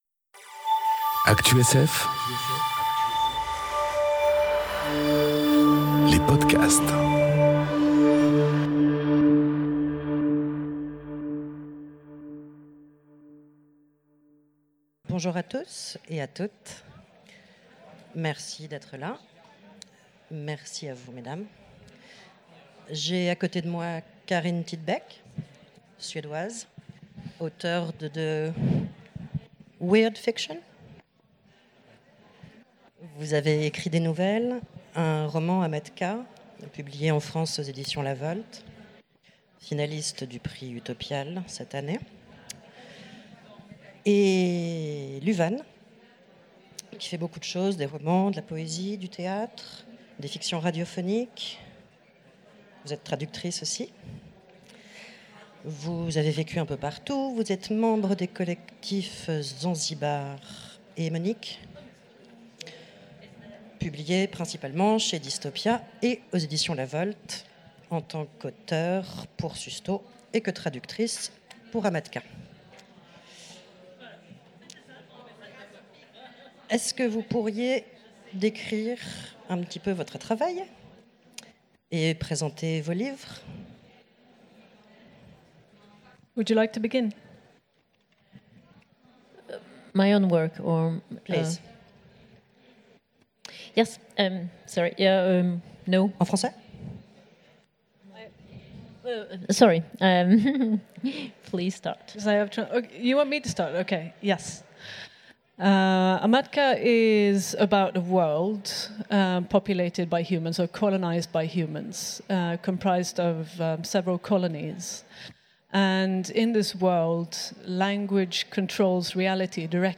Utopiales 2018 : Conférence
Rencontre avec un auteur